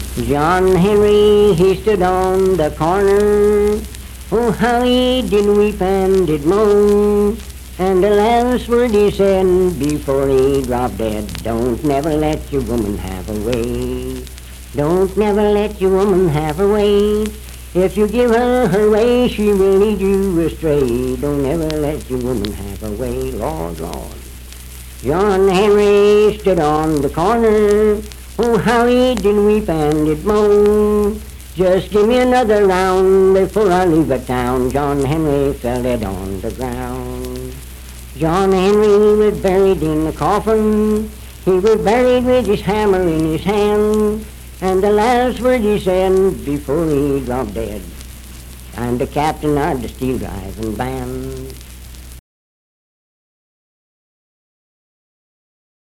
Unaccompanied vocal music
Performed in Sandyville, Jackson County, WV.
Minstrel, Blackface, and African-American Songs, Railroads, Death--Tragedy and Suicide
Voice (sung)